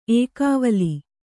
♪ ēkāvali